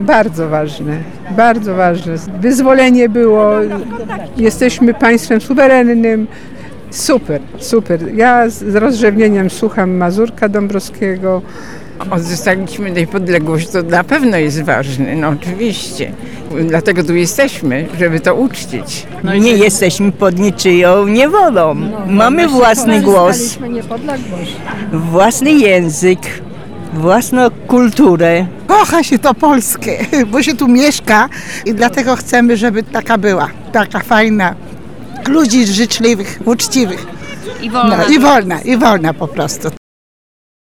O to zapytaliśmy uczestników dzisiejszych wydarzeń.